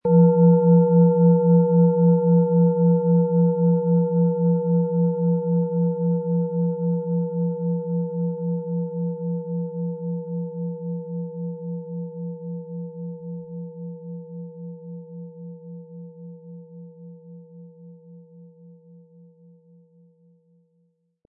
Alte Klangschalen - gesammelte Unikate
Ein unpersönlicher Ton.
• Tiefster Ton: Mond
Im Audio-Player - Jetzt reinhören hören Sie genau den Original-Ton der angebotenen Schale.
SchalenformBihar
MaterialBronze